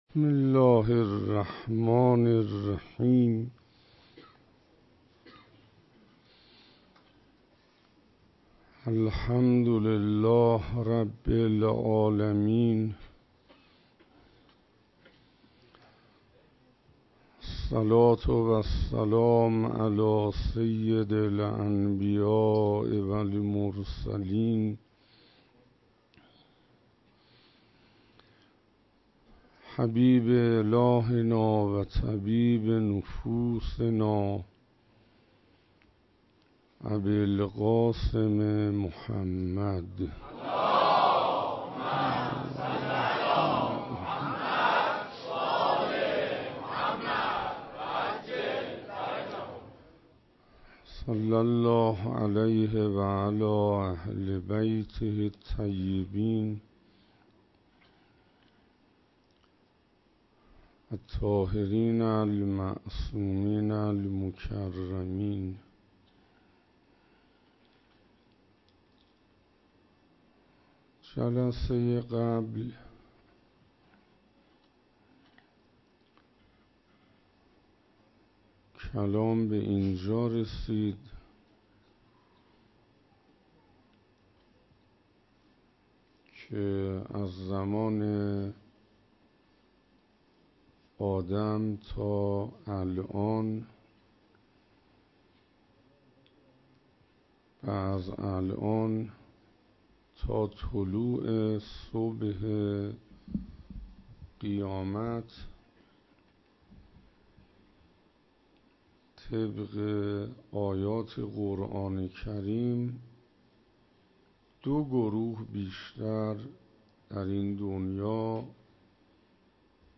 شب هفتم محرم 96 - حسینیه حضرت ابالفضل علیه السلام (تهرانپارس)